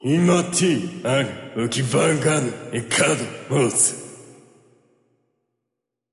Voice Lines
This section contains Dragon Language.